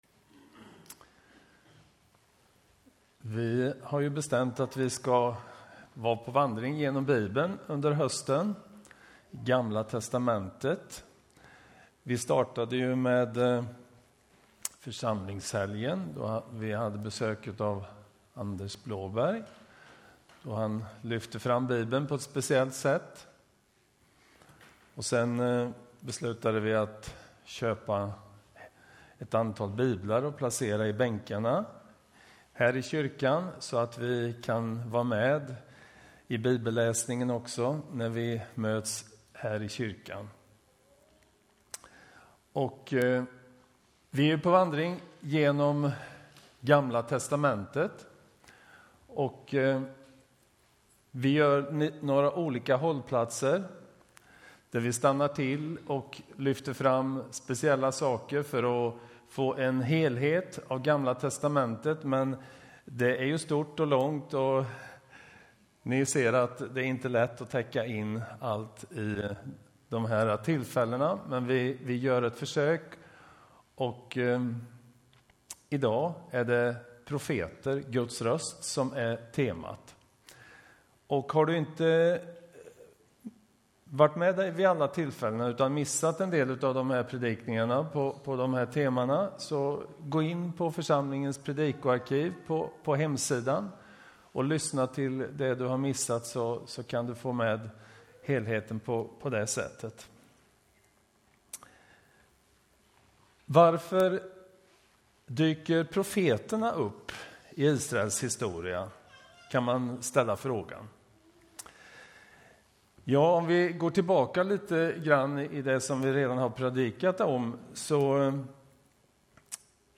Predikoserie: Genom GT (hösten 2017)